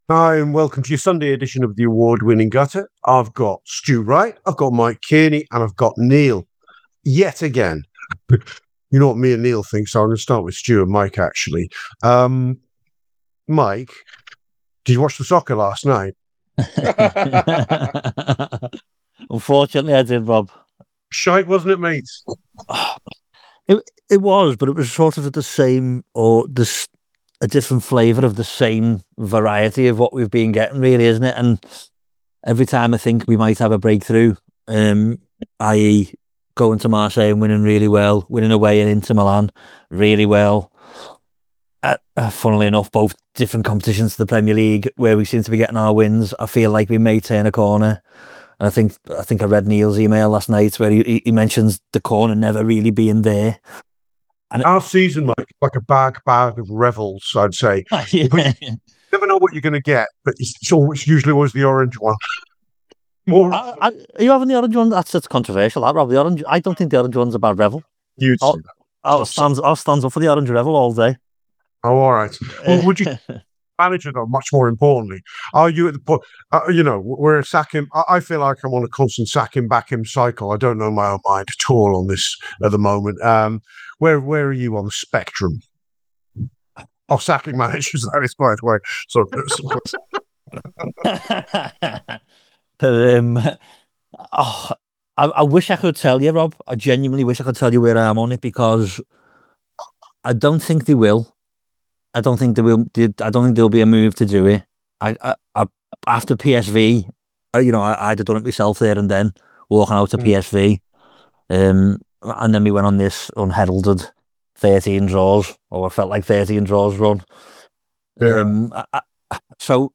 Below is a clip from the show – subscribe for more on the latest Liverpool transfer news…